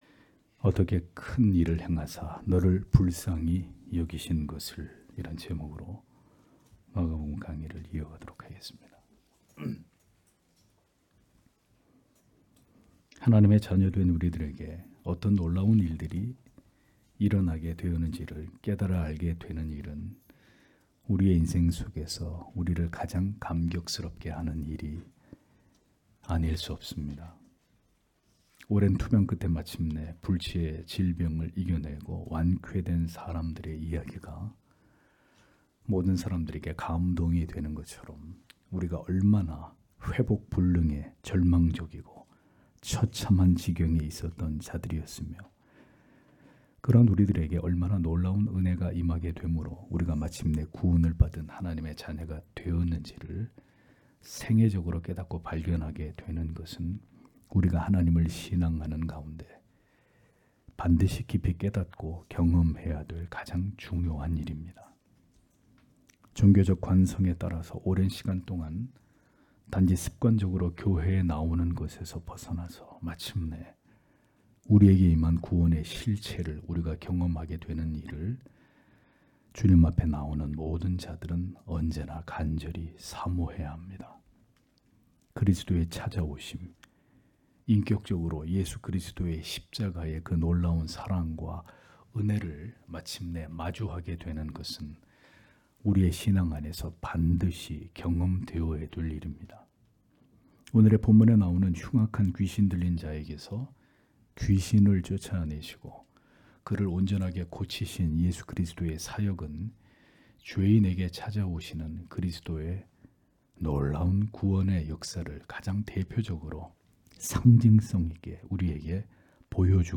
주일오전예배 - [마가복음 강해 17] 어떻게 큰 일을 행하사 너를 불쌍히 여기신 것을 (막 5장1-20절)